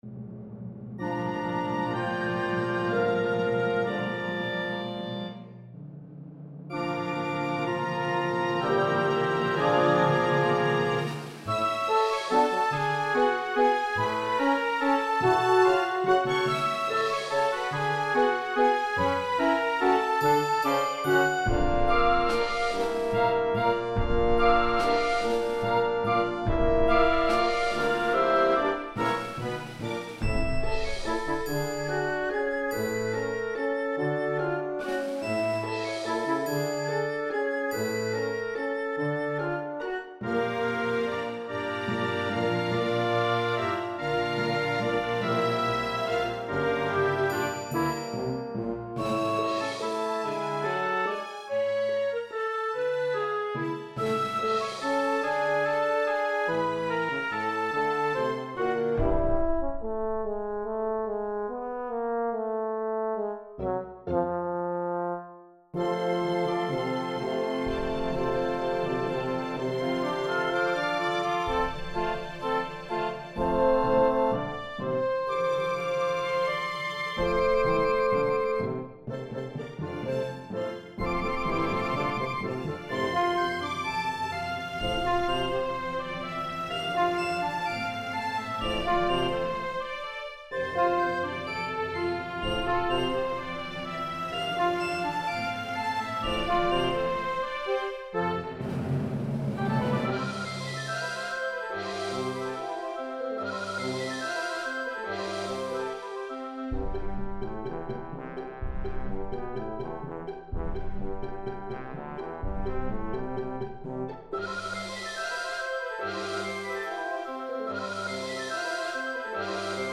El Circo de Ninos - Orchestral and Large Ensemble - Young Composers Music Forum